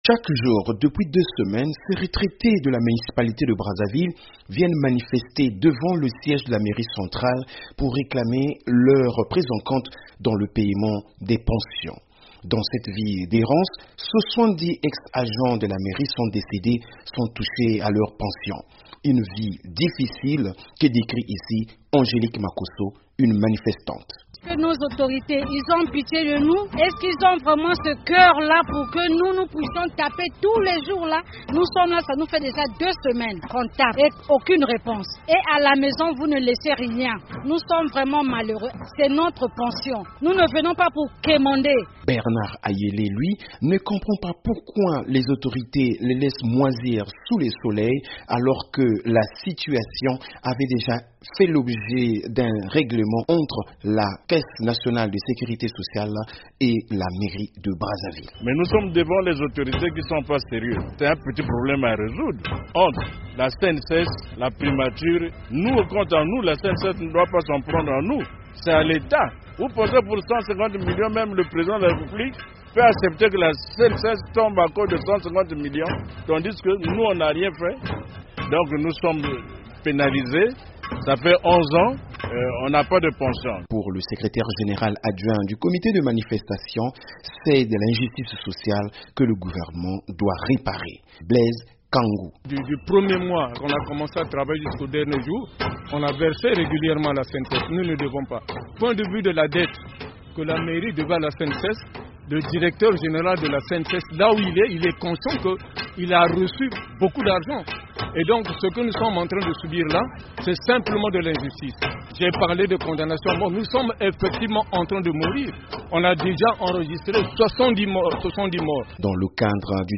Après une atente de plus de onze ans, plus de 250 anciens agents de la municipalité de Brazzaville mènent depuis des semaines une protestation bruyante pour exiger le paiement de leurs pensions.
Chaque jour depuis deux semaines, ces retraités de la municipalité de Brazzaville viennent manifester devant le siège de la mairie centrale pour réclamer leur prise en compte dans le paiement des pensions.
En attendant, les agents retraités de la mairie viennent défier le soleil et la pluie en tapant aux casseroles pour réclamer leurs droits.